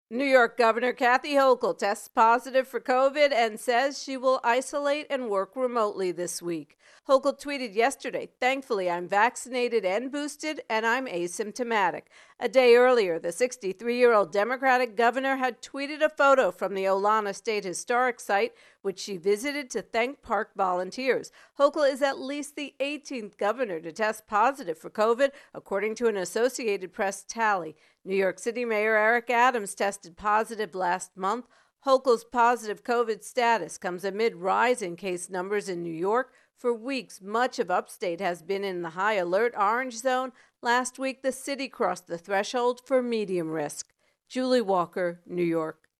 Virus Outbreak Hochul intro and voicer